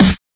Snare 13 (Reptile's Theme).wav